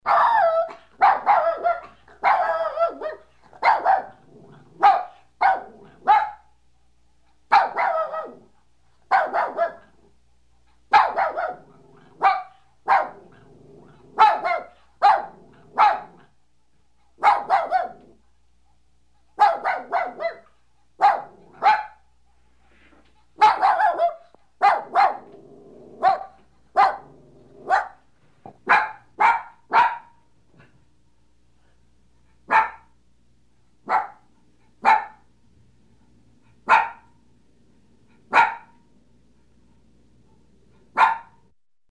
Звуки щенков
Звук крошечного песика с гавканьем и рычанием